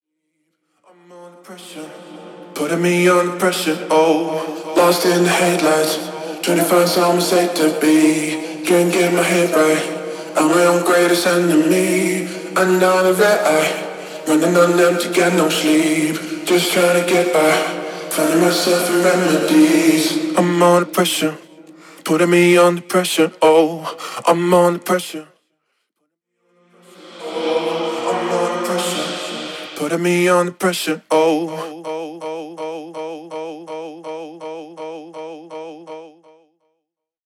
Demo of the 2023 update studio acapella :